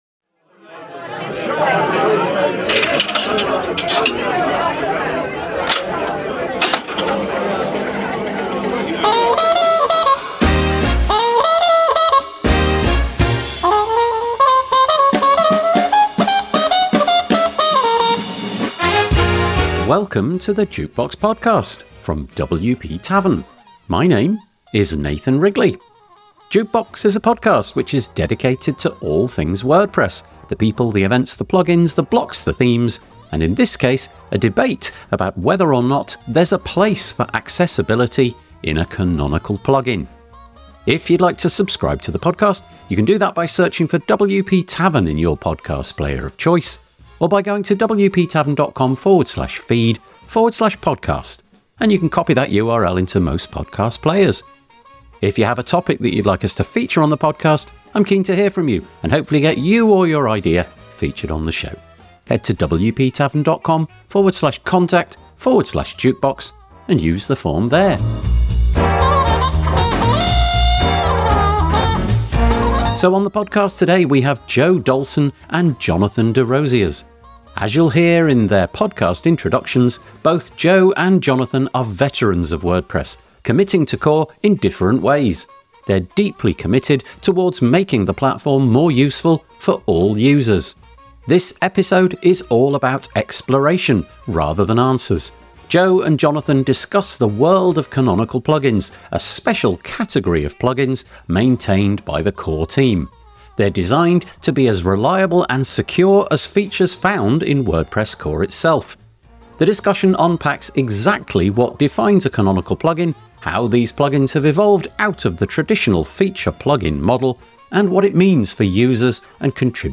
We interview people who are pushing change in how WordPress evolves. Plugins, Blocks, Themes, Community, Events, Accessibility and Diversity; we try to cover all the bases.